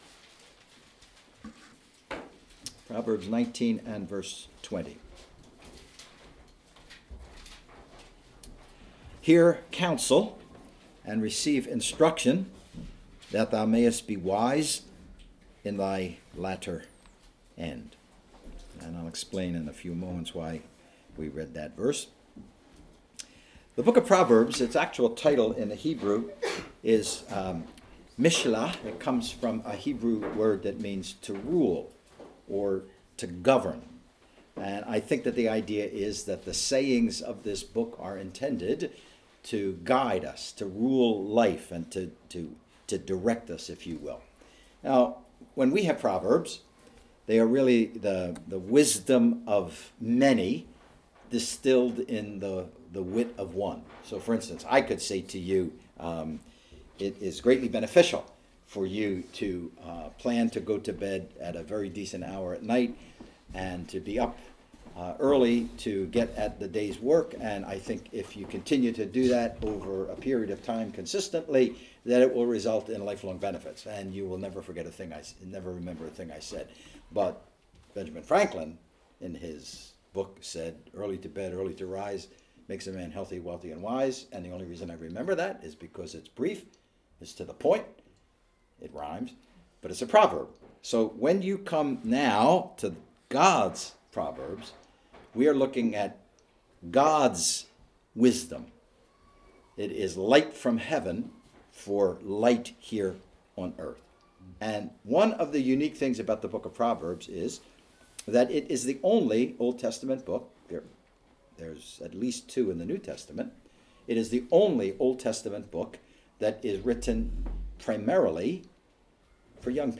preaches a succinct but incredibly practical message on 4 things he wish he knew when he was younger